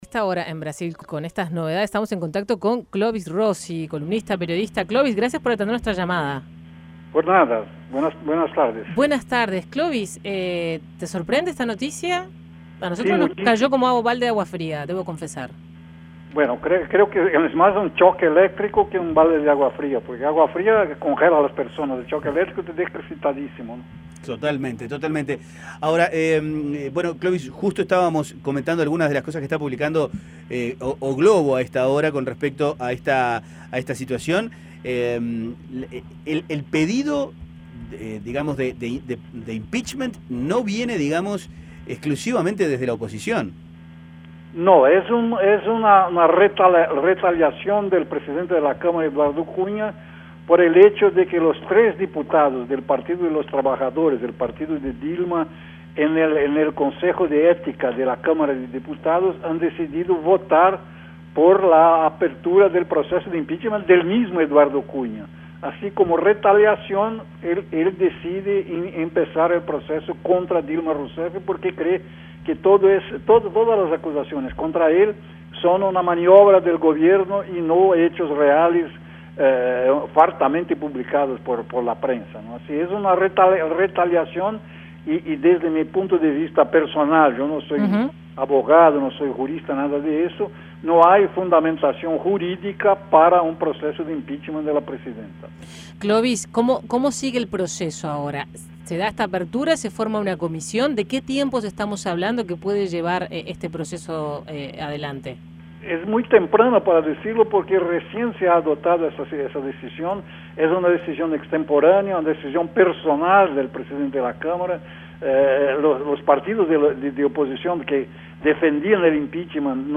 Escuche el análisis de Clovis Rossi
El analista político Clovis Rossi dijo a 810 que esta es la peor crisis que ha visto en sus 30 años de carrera.